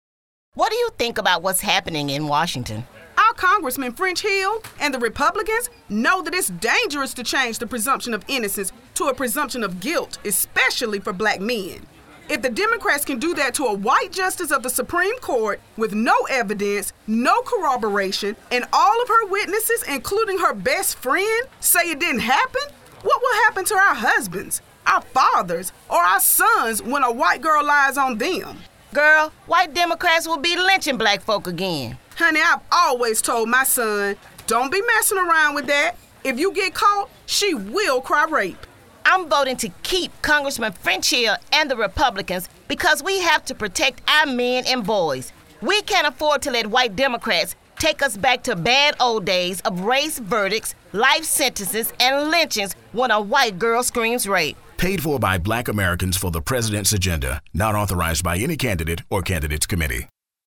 A political organization founded by African American supporters of Republican President Donald Trump is reaching out to voters with a shocking campaign ad airing on urban radio stations in several cities.